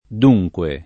d2jkUe] cong. — lett. e quasi antiq. adunque [ad2jkUe]; non usabile a ogni modo né con valore di frase ellittica (es.: dunque…? che c’è?) né con valore di s. m. (es.: venire al dunque)